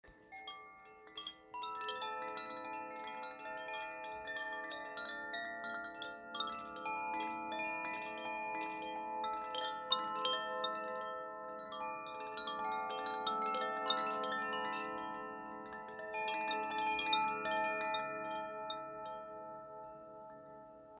Laissez leurs notes fluides vous guider à travers les courants de vos émotions.
Une combinaison puissante fraîche et fluide.